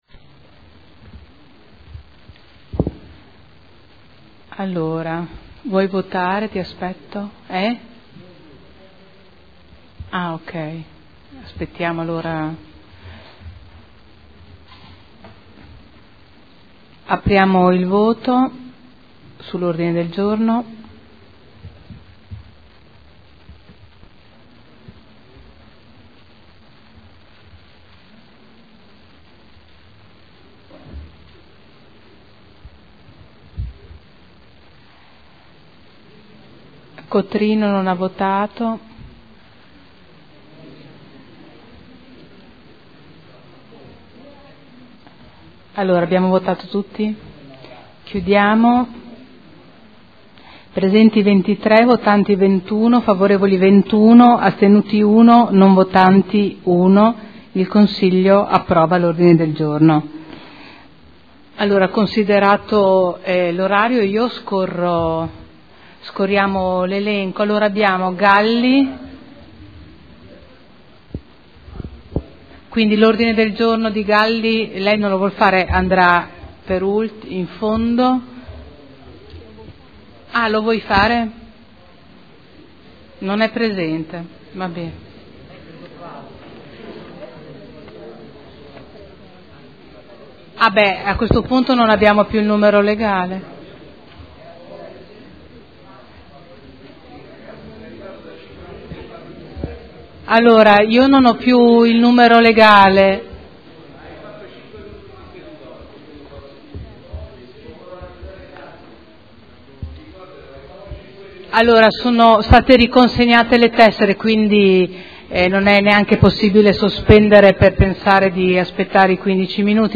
Presidente
Seduta del 6 marzo.